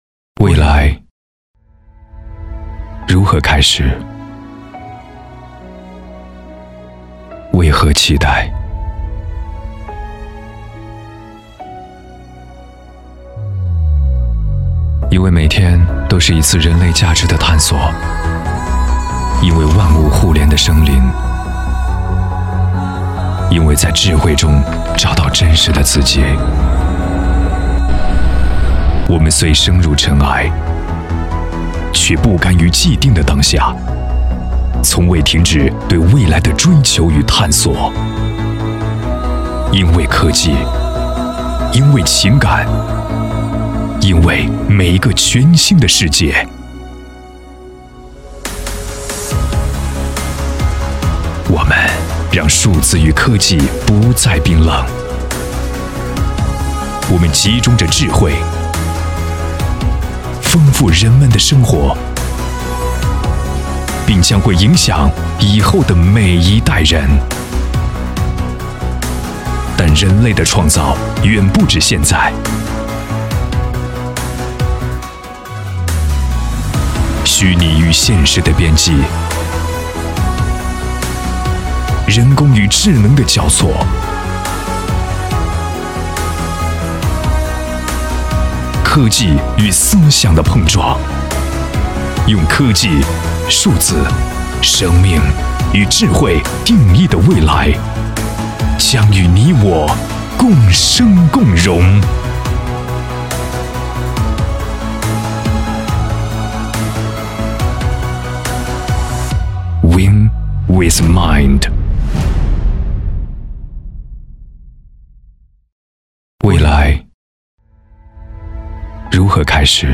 宣传片专题片配音：150元/分  800元起配；广告配音：400元/条
• 男S312 国语 男声 专题宣传（科技 大气） 2016中国智慧峰会 大气浑厚磁性|沉稳